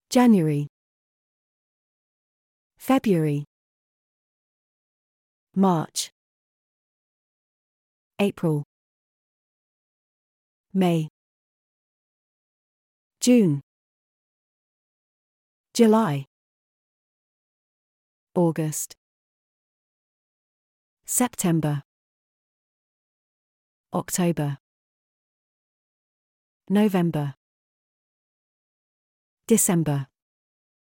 Давайте послушаем, как эти слова звучат на американском и британском английском.
Британский английский:
month-of-the-year-british-1.mp3